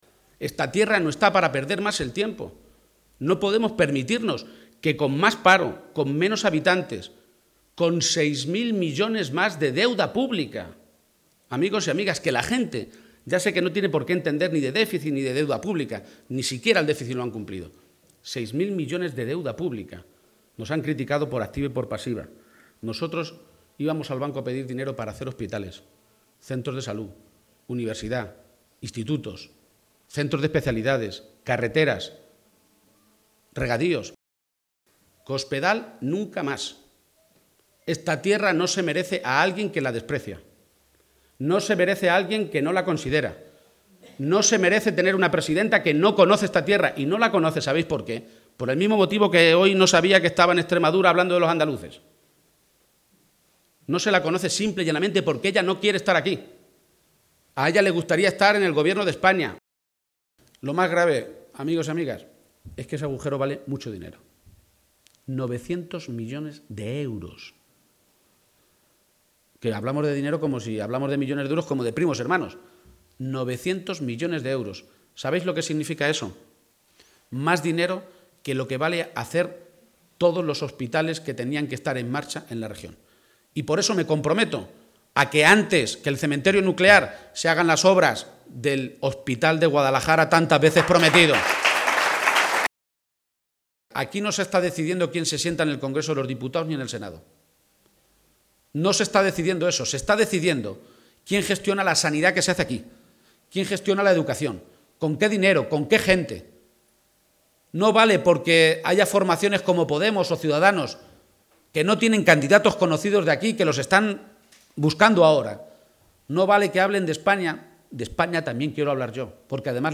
El candidato socialista a la Presidencia de la Junta asiste al acto de presentación de la candidatura del PSOE en Cabanillas del Campo (Guadalajara)